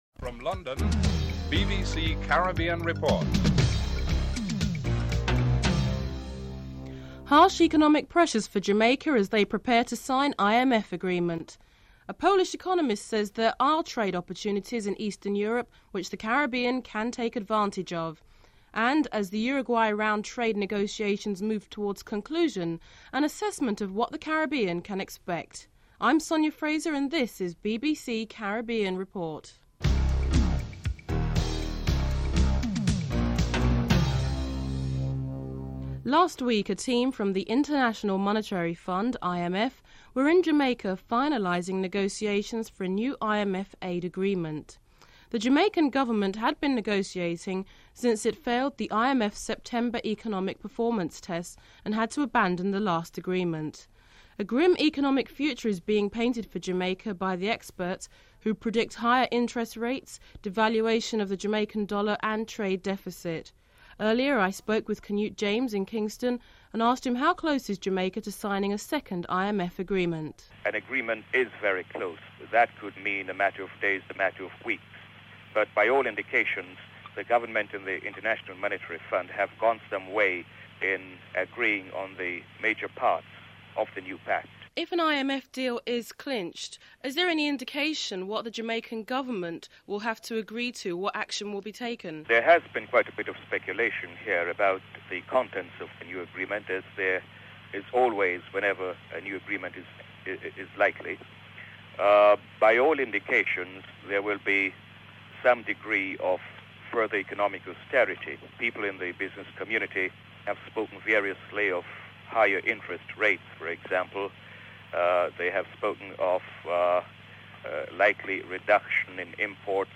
Following the Financial News, an economic correspondent examines the implications of the completion of the Uruguay Round of negotiations in December 1990 on Caribbean nations.
1. Headlines (00:00-00:34)
4. Financial News (08:18-10:00)